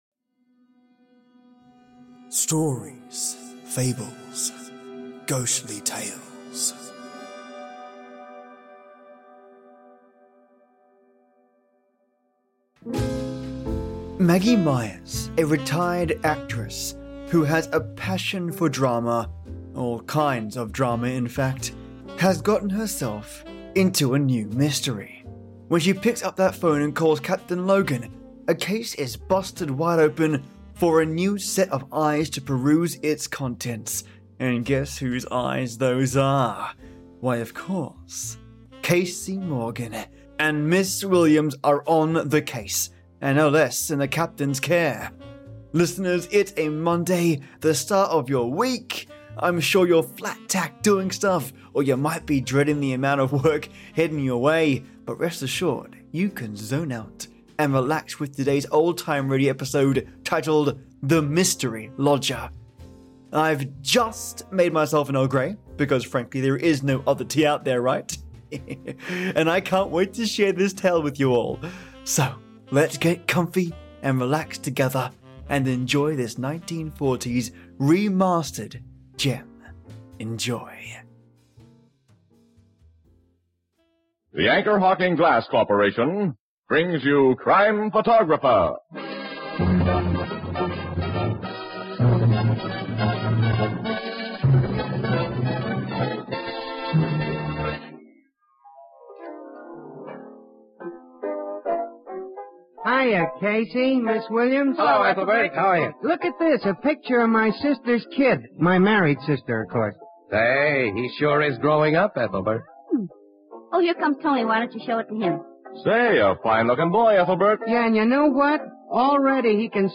Old Time Radio
So let’s get comfy, and relax together, and enjoy this 1940’s gem.